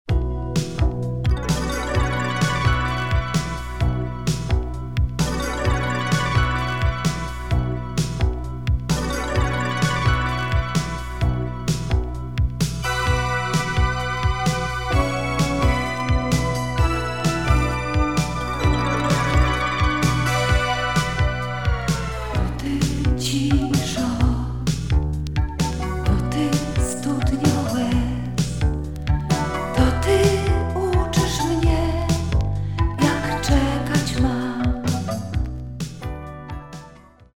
some killer spacy funky beats
Breaks & Loops